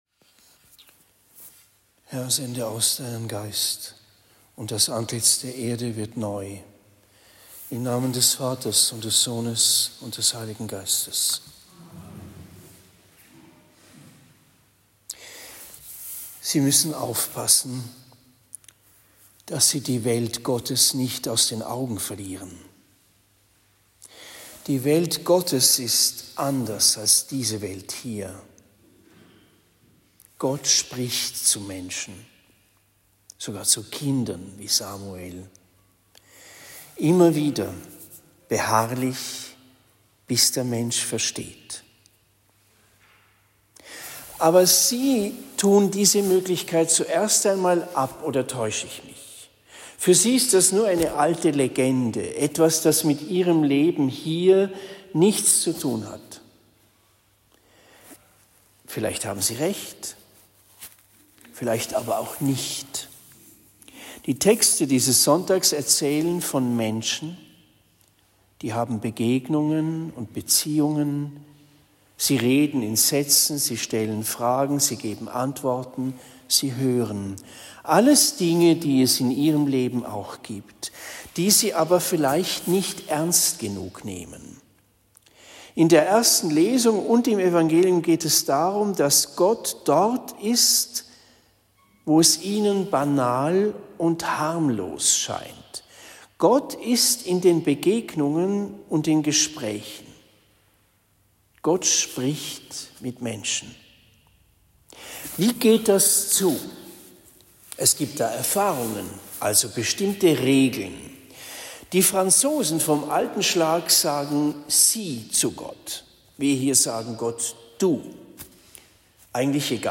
Predigt in Bischbrunn Heiligste-Dreifaltigkeit am 13. Jänner 2024